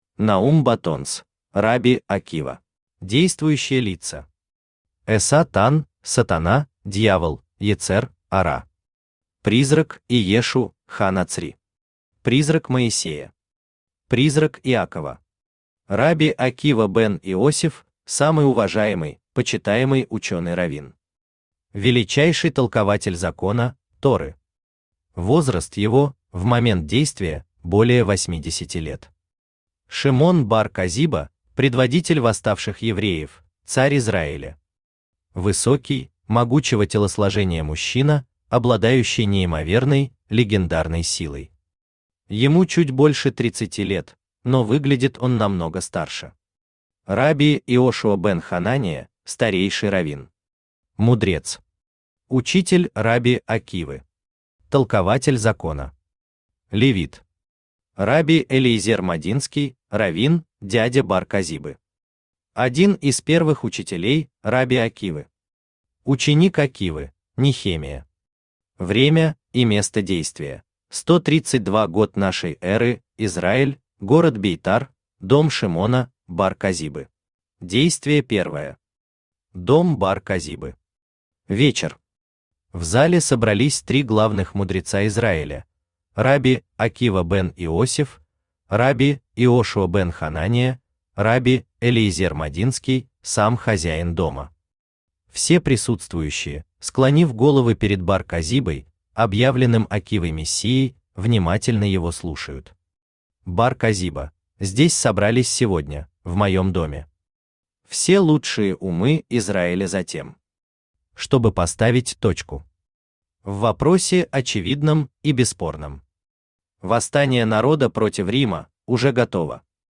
Аудиокнига Рабби Акива | Библиотека аудиокниг
Aудиокнига Рабби Акива Автор Наум Баттонс Читает аудиокнигу Авточтец ЛитРес.